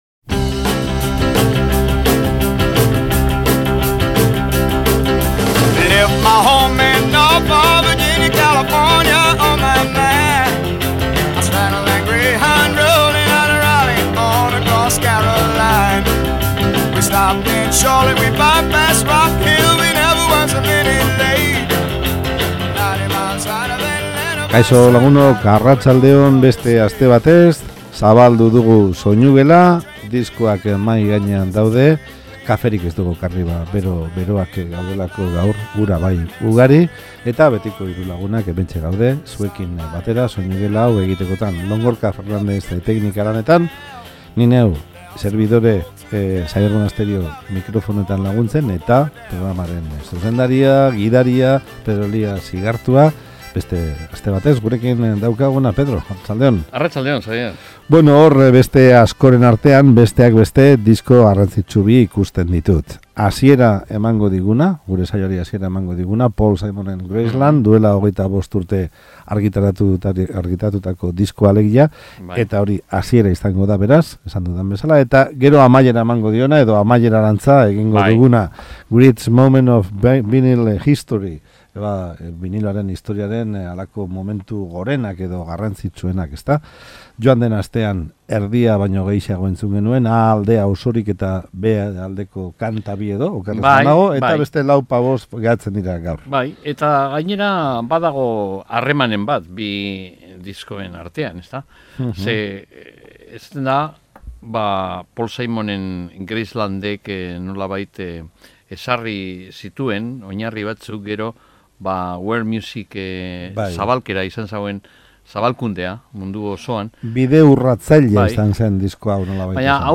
hauek biak binilotik